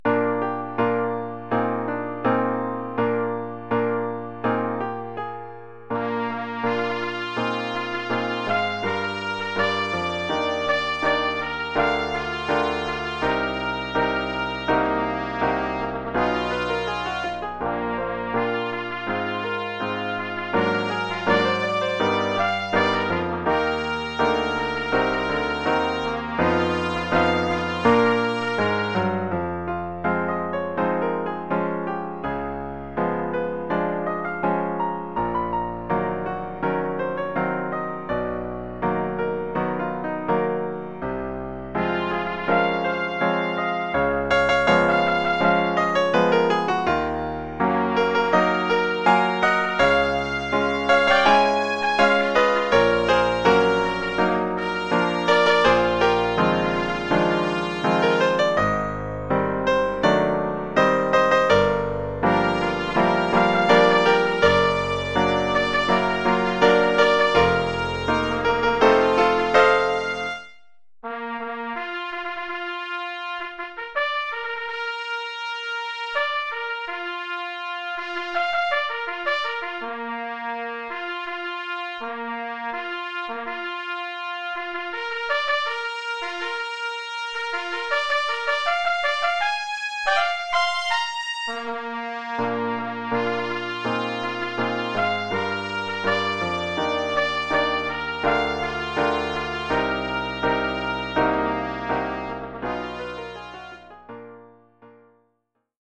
Oeuvre pour clairon sib et piano.